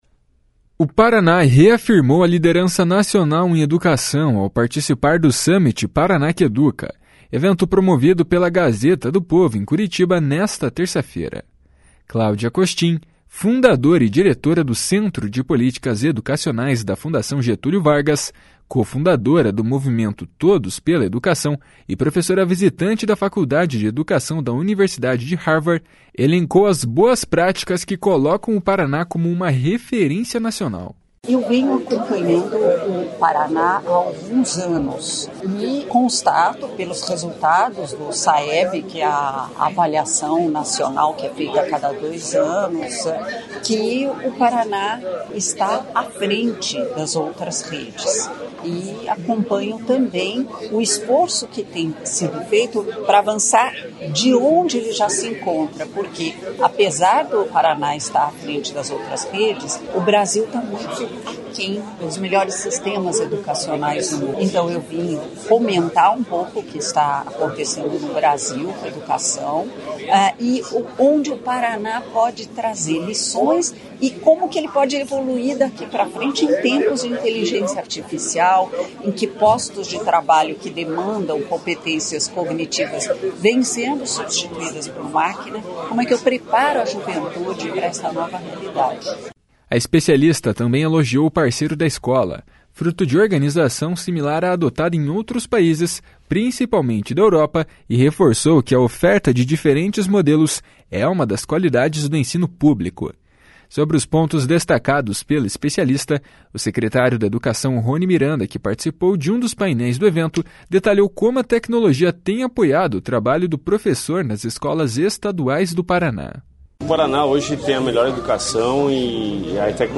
// SONORA CLÁUDIA COSTIN //